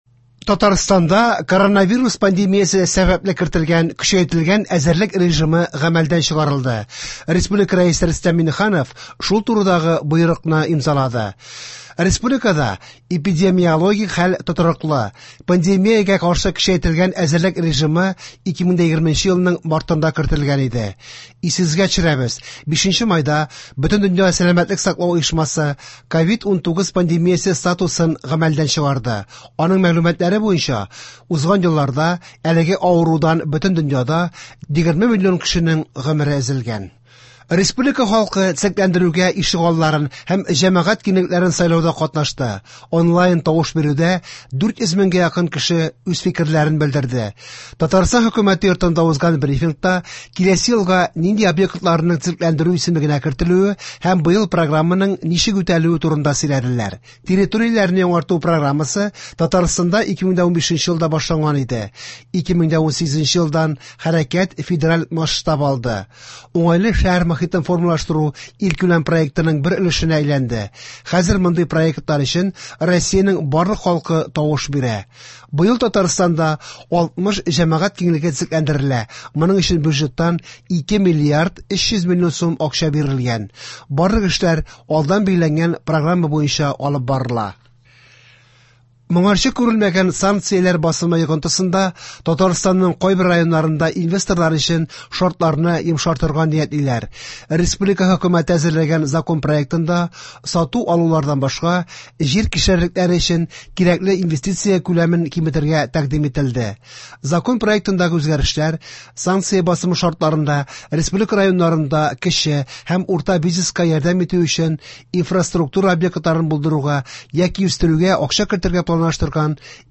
Яңалыклар (14.06.23)